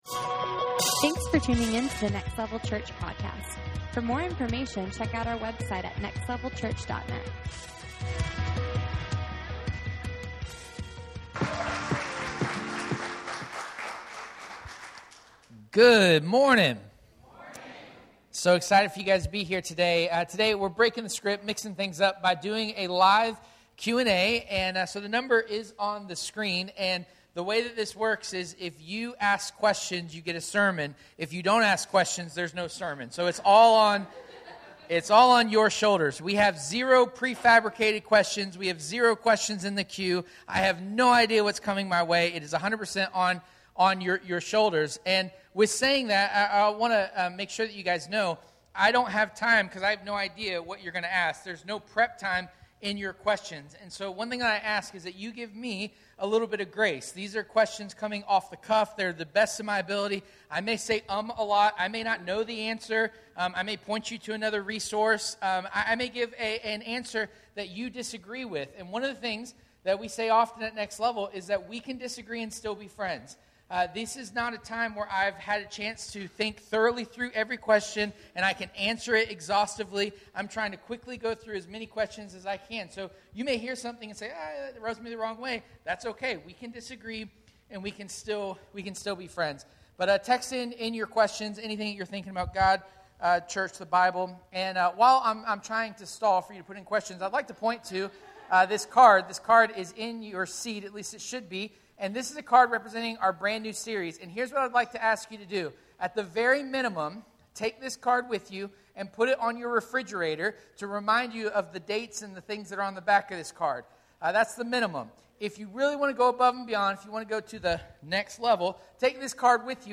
Q & A – 11:15 Service